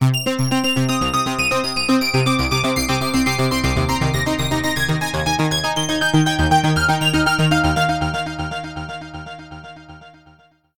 Pieza de música Tecno
tecno
melodía